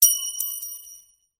コインが地面に落ちた音 着信音
のどがカラカラ状態でタイミングよく近くに自動販売機が！運よくポケットに500円が入っていた。喉の渇きが極限状態であったため慌ててポケットから取り出すとき落としてしまった！その時の効果音。